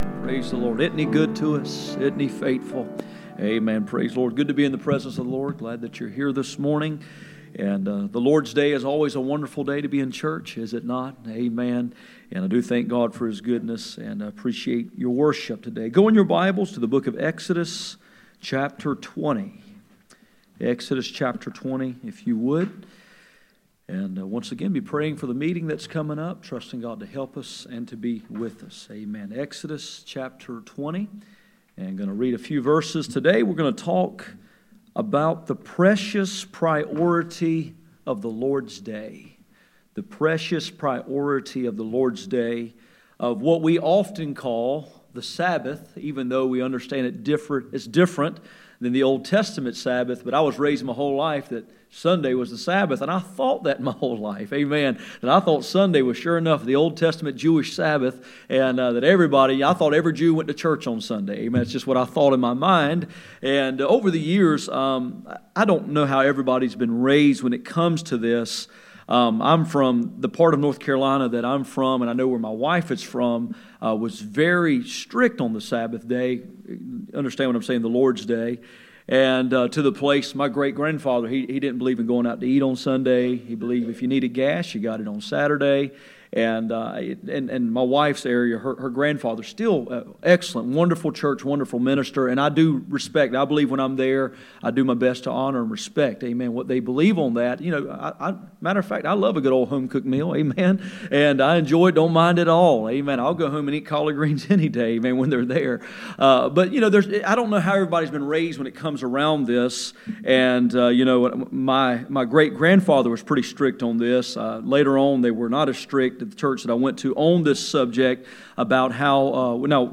None Passage: Exodus 20:8-11 Service Type: Sunday Morning %todo_render% « How true is truth?